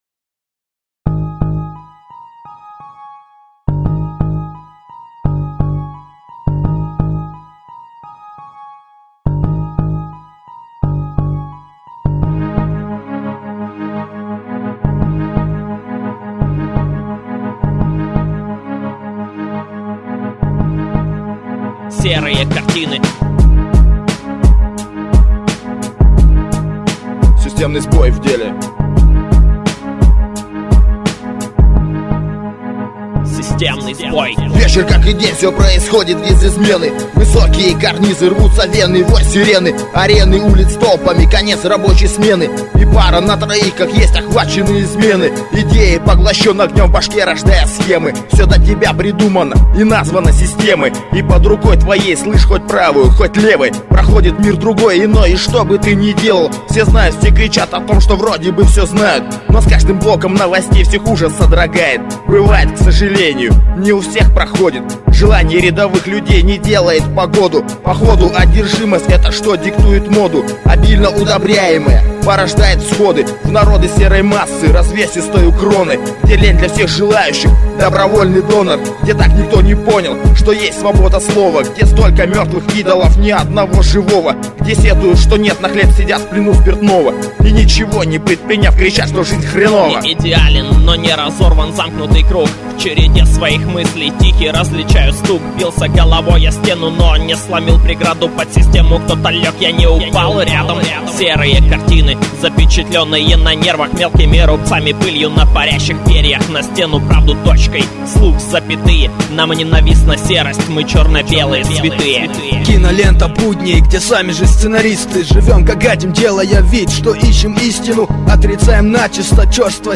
undeground rap
пересведенный трек